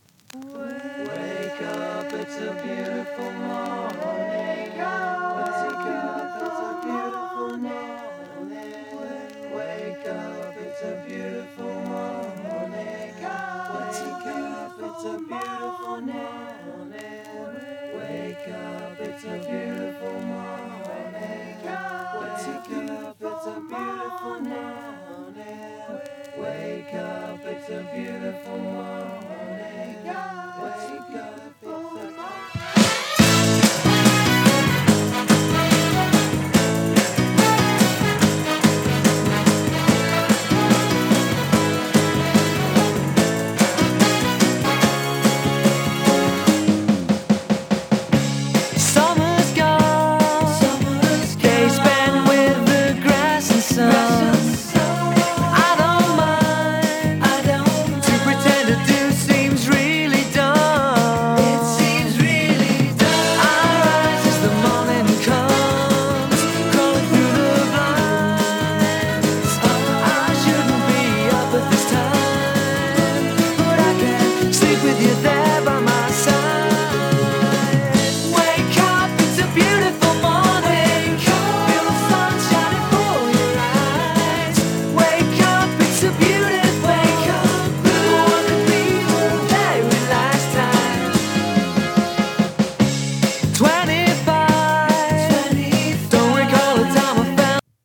ブリットポップ
華やかなホーンのイントロに胸が踊るブリットポップ名曲の
試聴はLPからの流用。